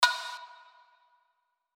SouthSide Snare Roll Pattern (17).wav